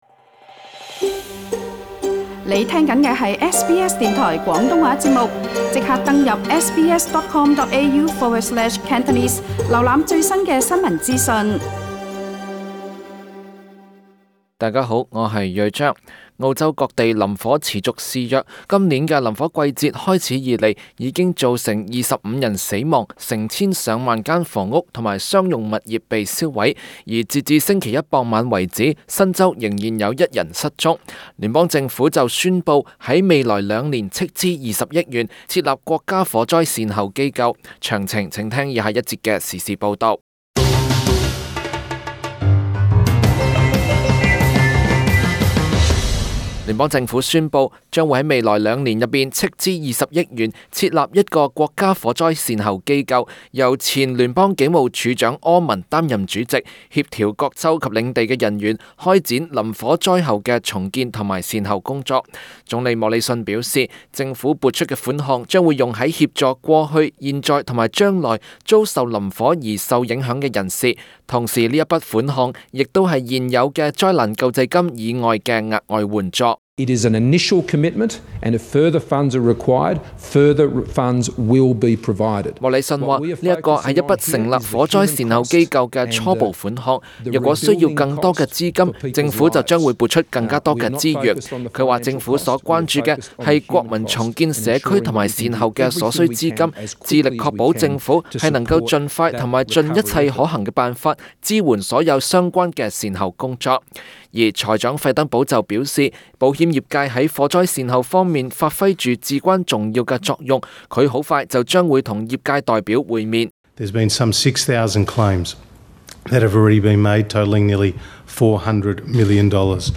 Scott Morrison speaks during a media conference on the governments' bushfire response Source: AAP